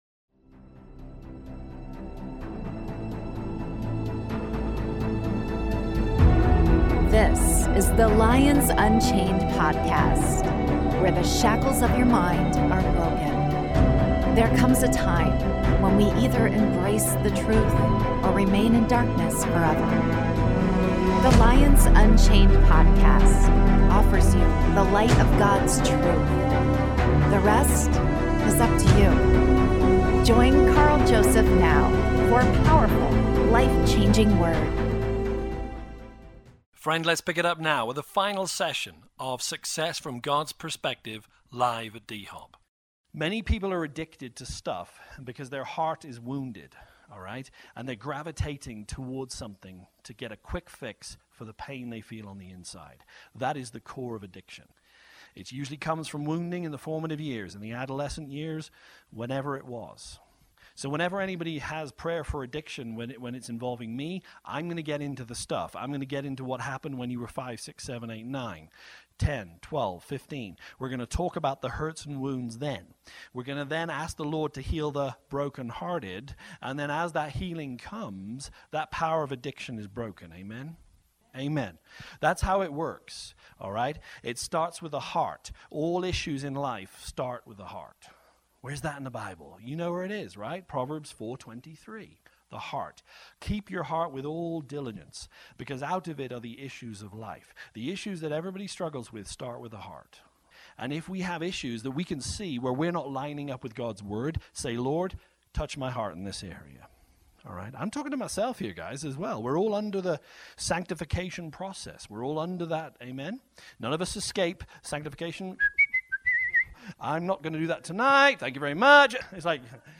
Success from God’s Perspective: Part 3 (LIVE)